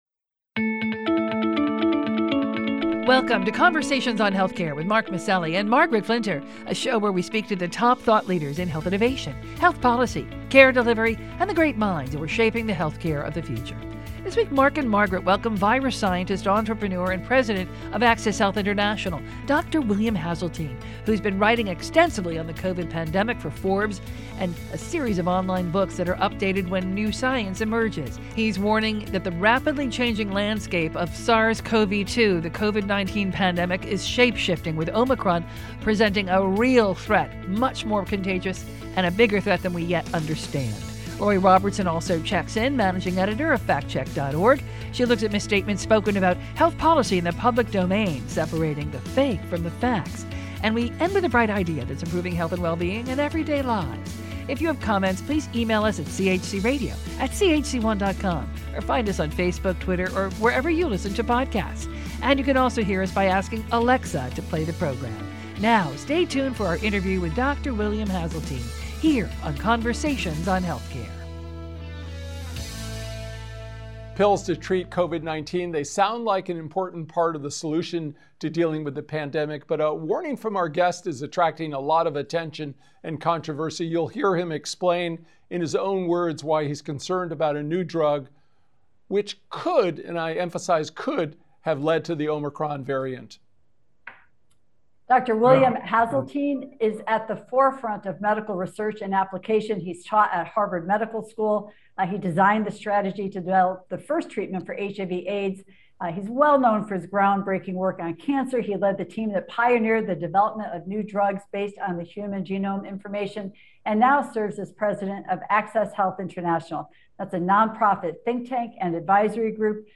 Omicron Could Be as Deadly as Delta, Boosters’ Short Protection &‘Dangerous’ Oral COVID Pill from Merck: A Talk With William Haseltine